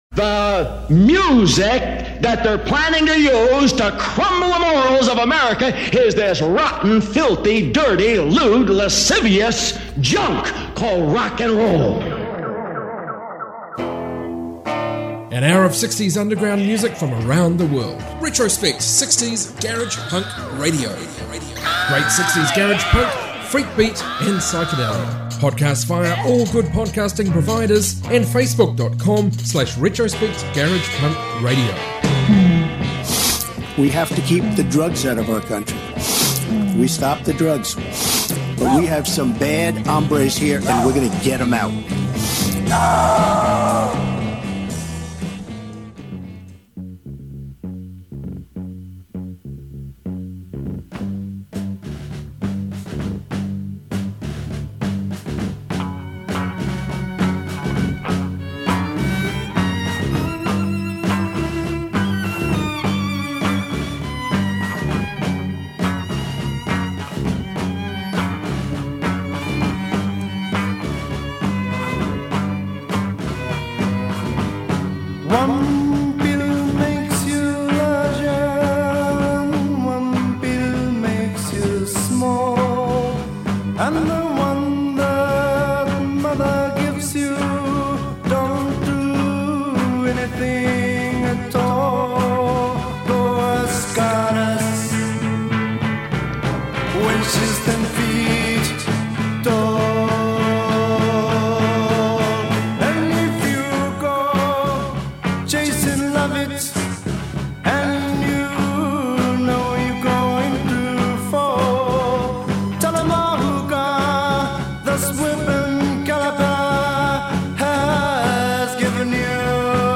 60s garage rock garage punk freakbeat psychedelia